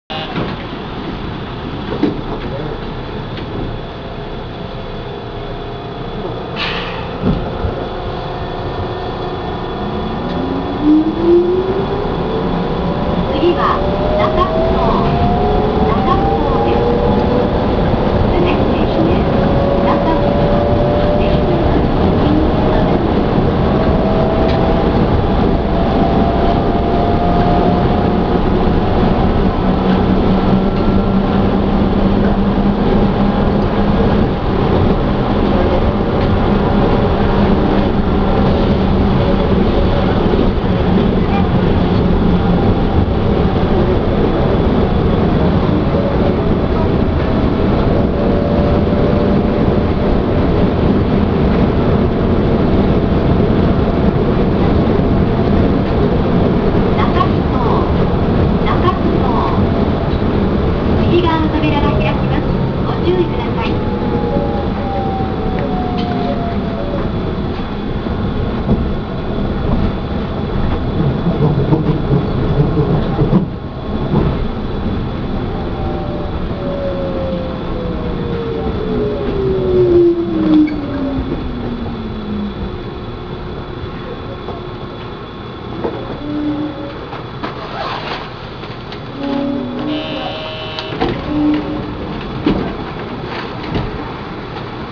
・100A系走行音
【南港ポートタウン線】トレードセンター前〜中埠頭（1分34秒：516KB）
走行装置はチョッパ制御（正確には可逆式サイリスタレオナード制御）。車内放送の声も地下鉄とは異なるようです。